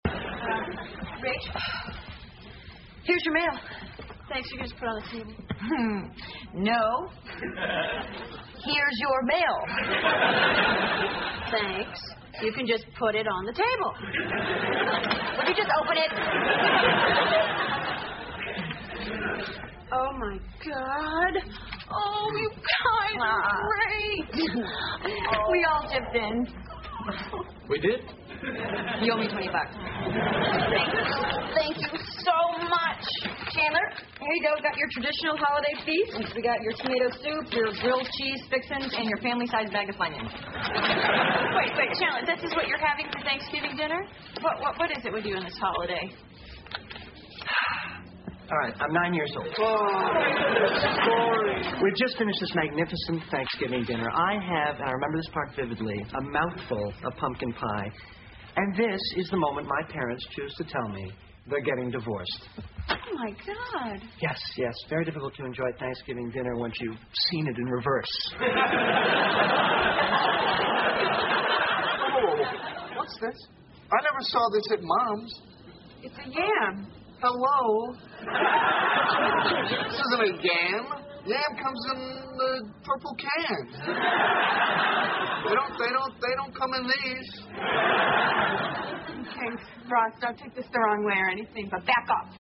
在线英语听力室老友记精校版第1季 第104期:气球飞走了(5)的听力文件下载, 《老友记精校版》是美国乃至全世界最受欢迎的情景喜剧，一共拍摄了10季，以其幽默的对白和与现实生活的贴近吸引了无数的观众，精校版栏目搭配高音质音频与同步双语字幕，是练习提升英语听力水平，积累英语知识的好帮手。